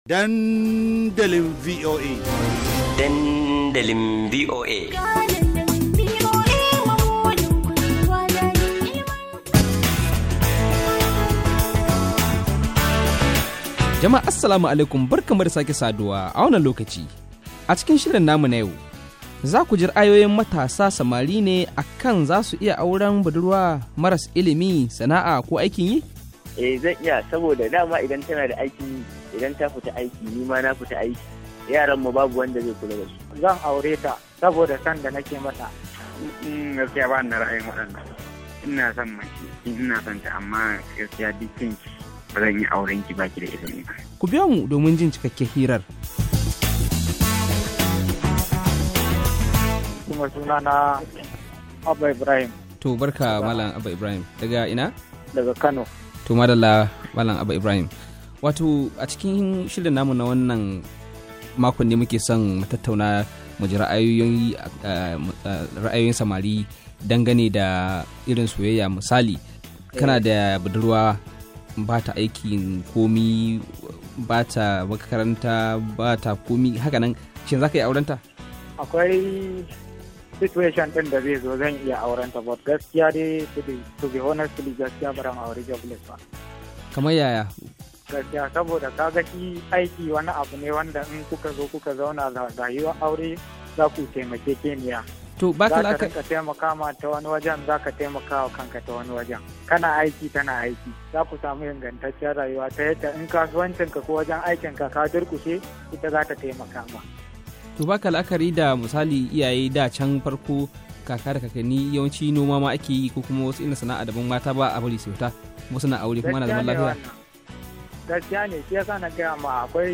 Kamar yadda muka saba a kowane karshen mako, shirin samartaka na dandalinvoa na kawo maku hira da matasa samari da ‘yan mata akan lamurra daban daban da suka shafi zamantakewa, soyayya, da sauran batutuwa makamantan haka, a wannan satin mun sami zantawa ne da matasan domin jin ra’ayoyinsu akan...